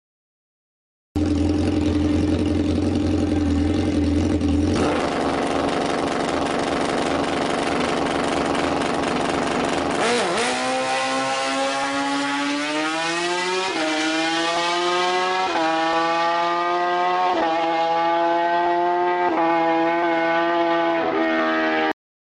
Yamaha FZ1
Modifikationen: Laut